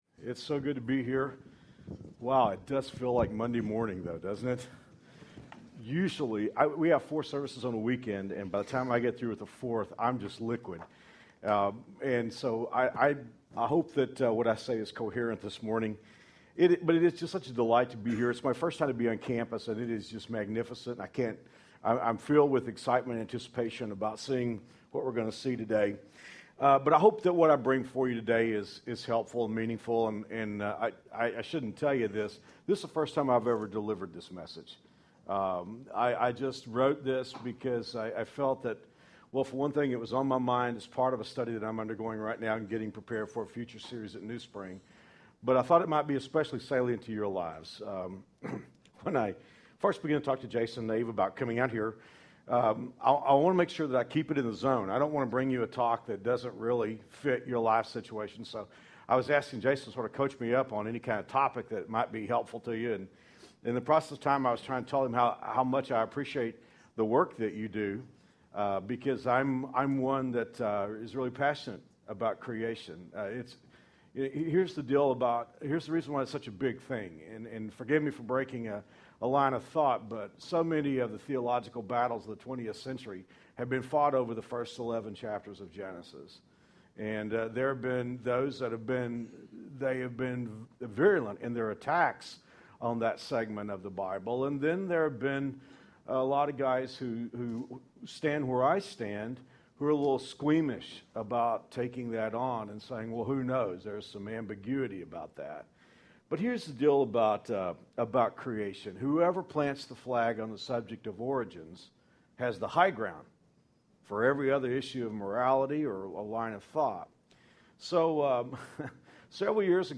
share a devotional.